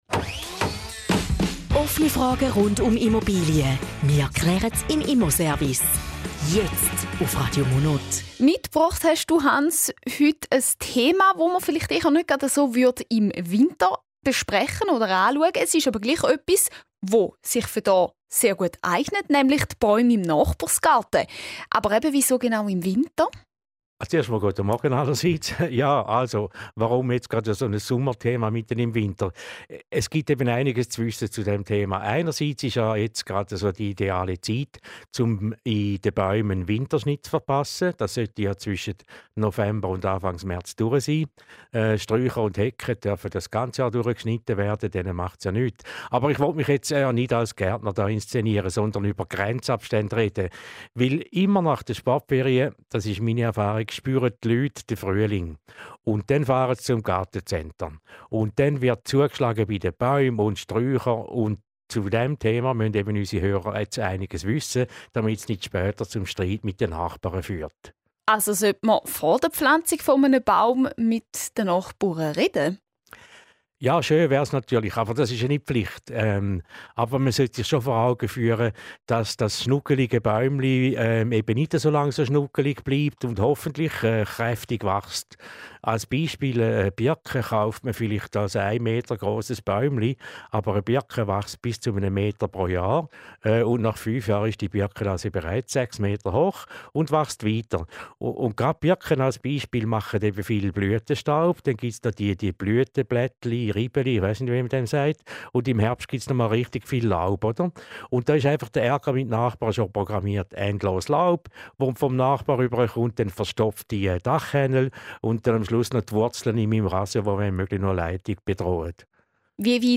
Zusammenfassung des Interviews zum Thema "Bäume in Nachbars Garten":